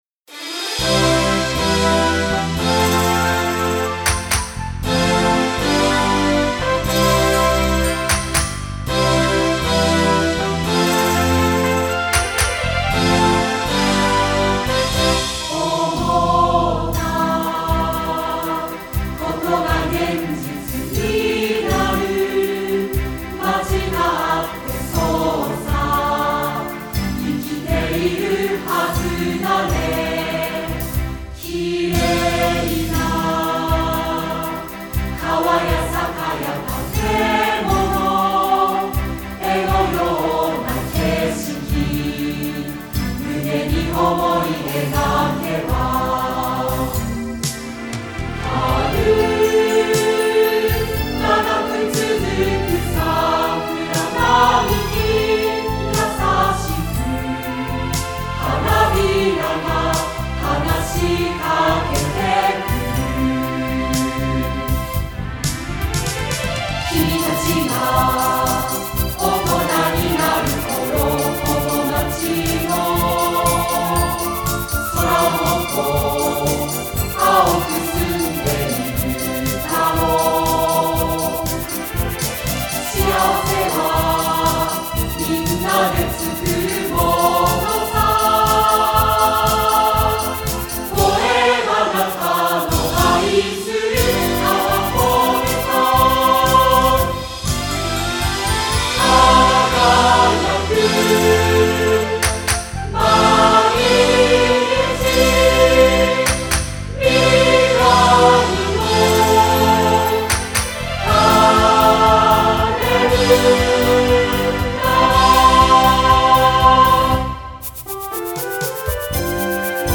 2015年3月11日　東亜学園ホールにて録音
斉唱（音楽ファイル(MP3)：9,742KB）